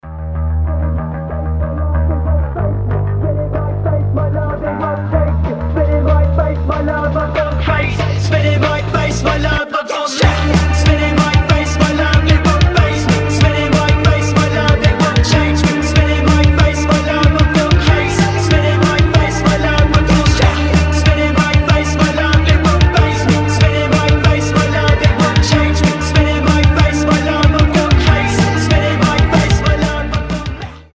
• Качество: 320, Stereo
качающие
alternative
indie rock